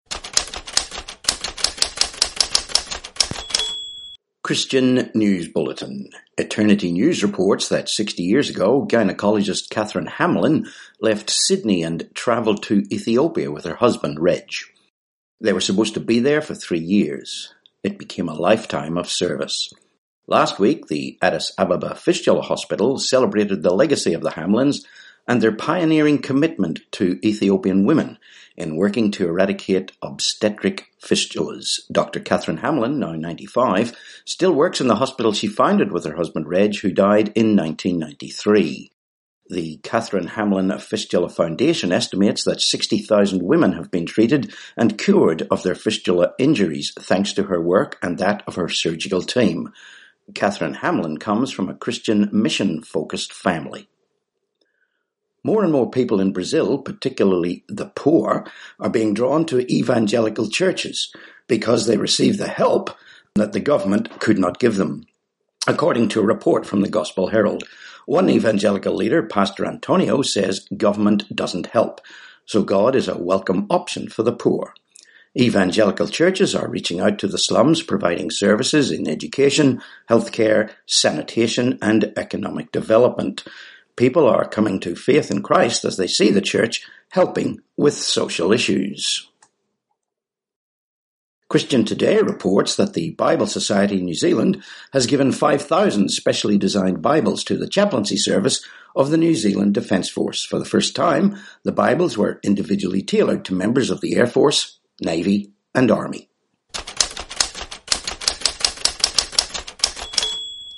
9Jun19 Christian News Bulletin